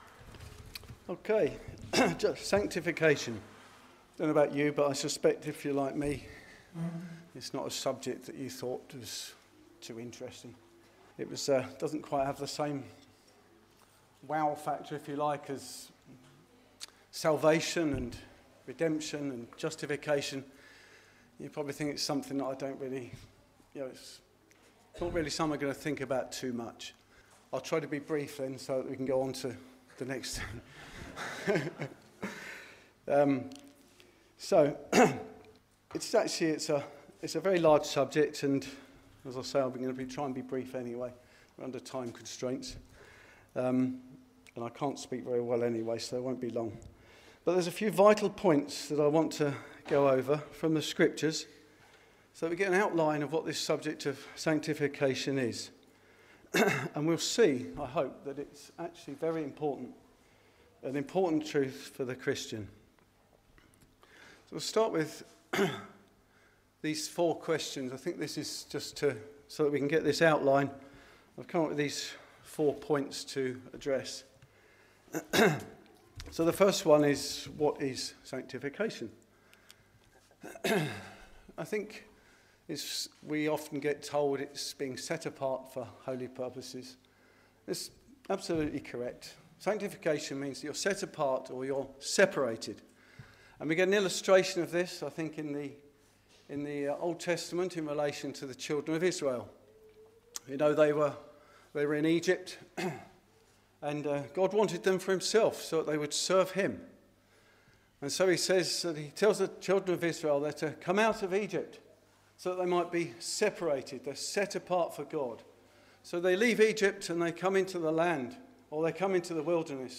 This talk recorded at Refresh 25 explores how God sets believers apart for His purposes and transforms them into the image of Christ. It examined the ongoing work of God renewing our hearts and minds, leading to a life that reflects Christ.